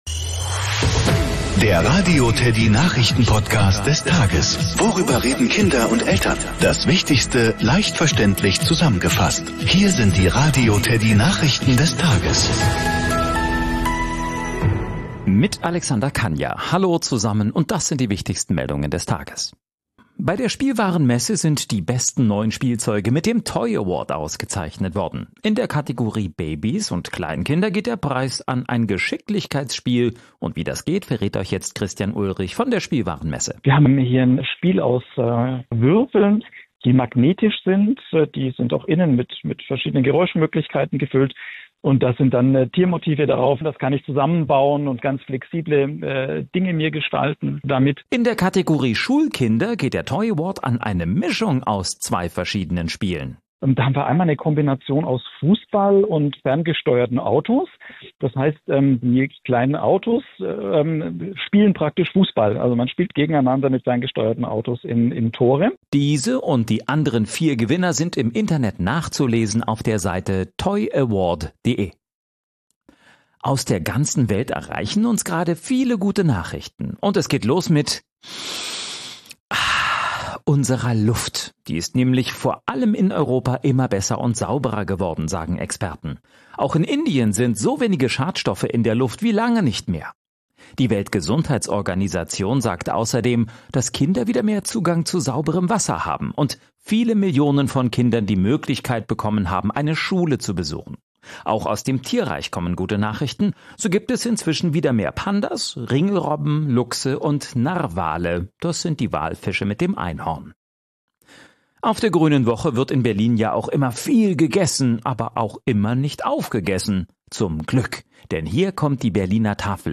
Der Radio TEDDY-Nachrichtenpodcast des Tages - der tägliche Überblick über die wichtigsten Themen des Tages.